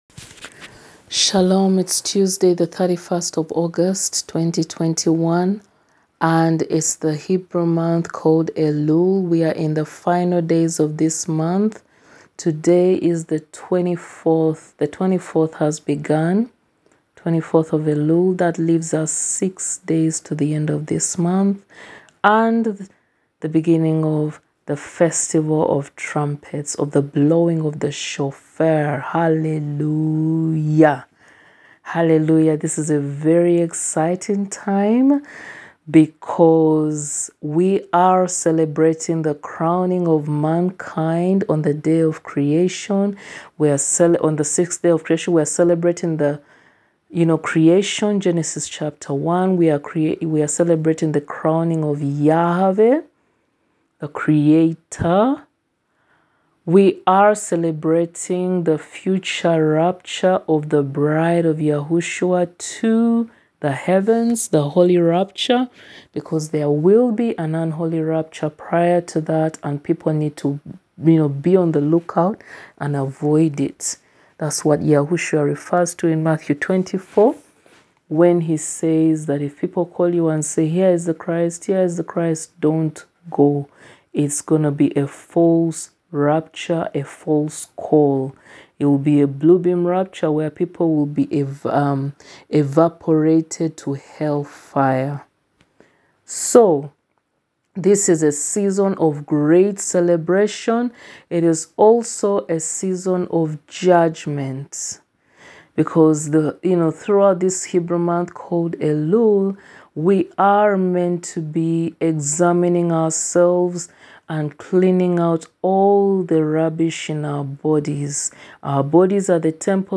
recording_278_part_2_teaching_cain__abel.wav